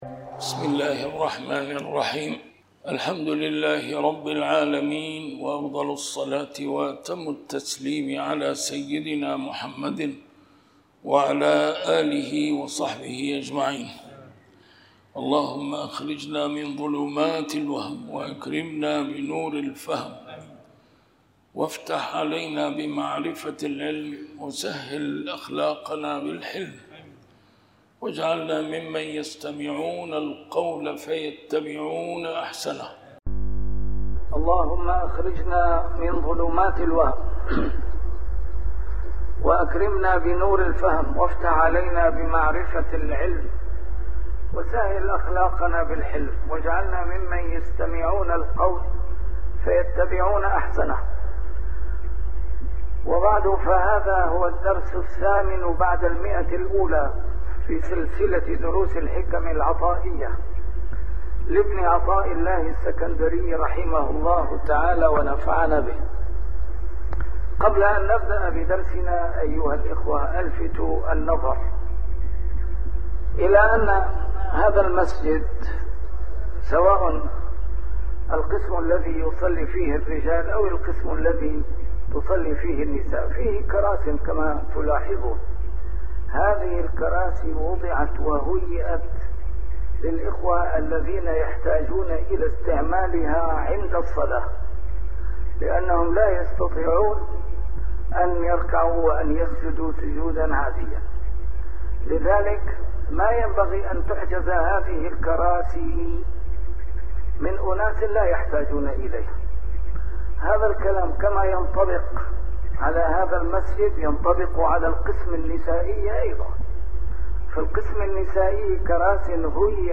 الدرس رقم 108 شرح الحكمة 88